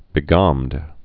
(bĭ-gômd)